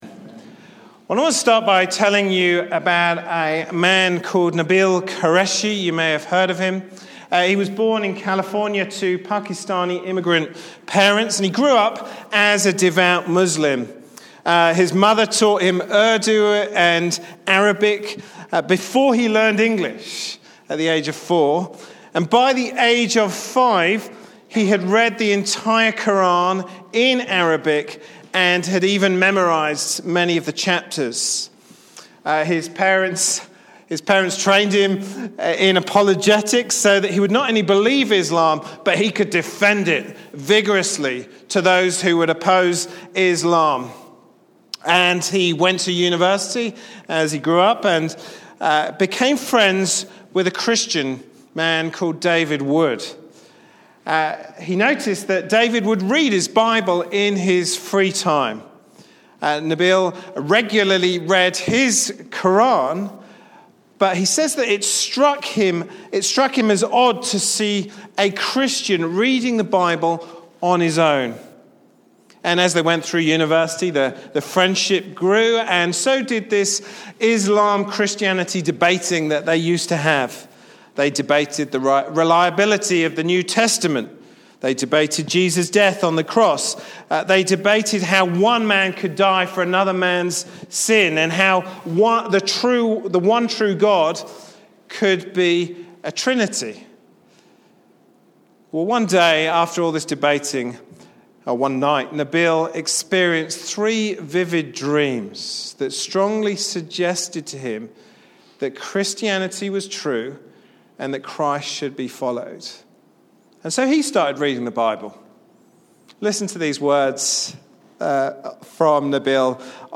Sunday morning service Topics